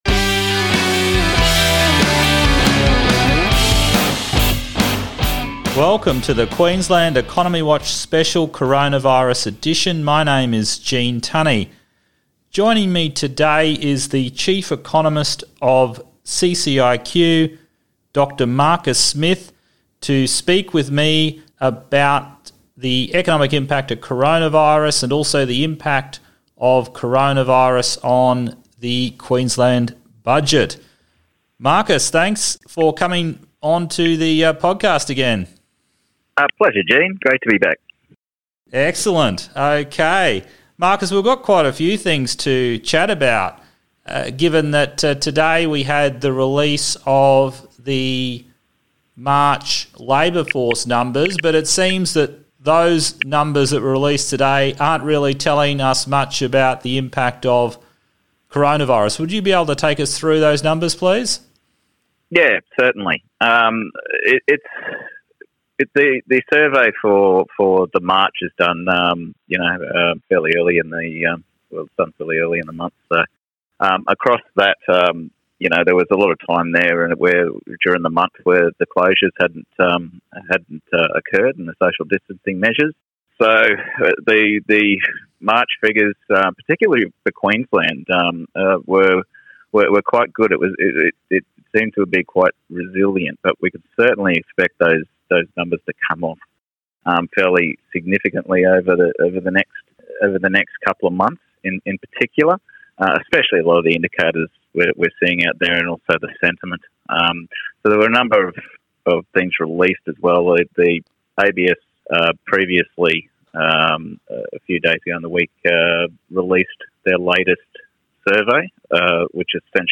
Highlights of the discussion include: